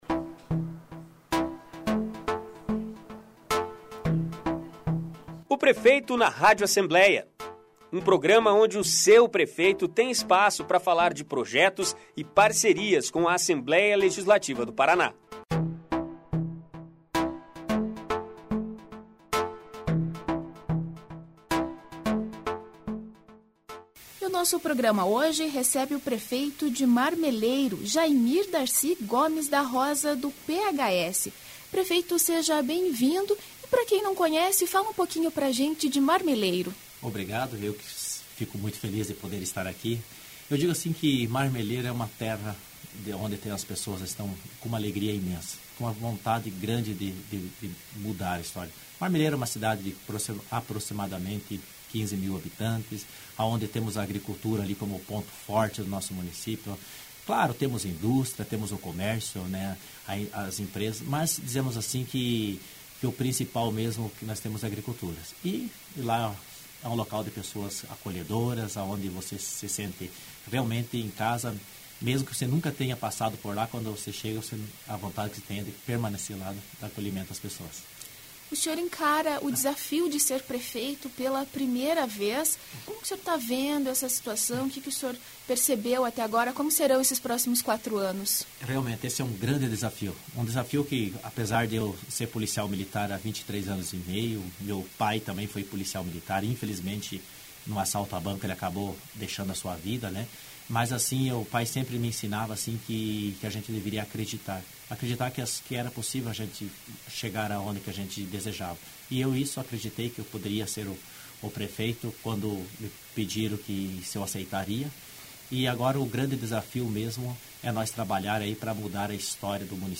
Ouça a entrevista que o prefeito de Marmeleiro, Jaimir Gomes da Rosa (PHS) deu À jornalista